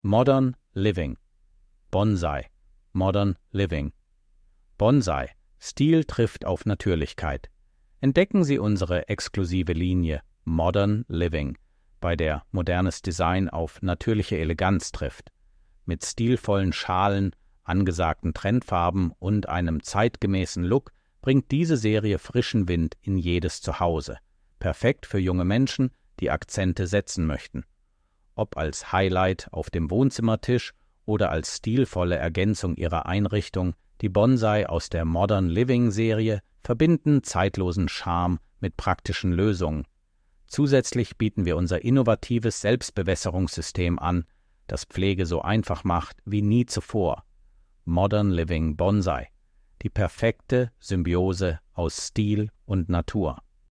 Audio-Datei mit vorgelesenem Text über die Gärtnerei Hohn.